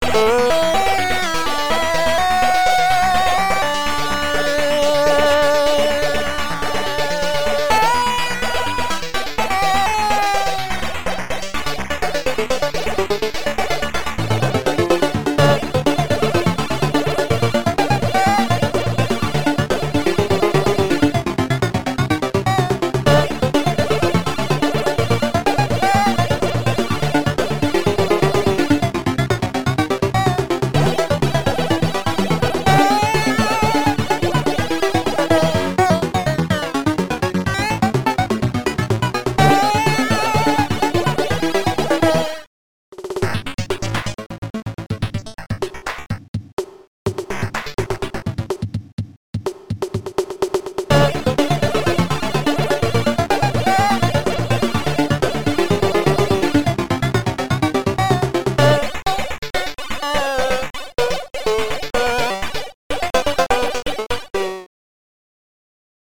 JamCracker Module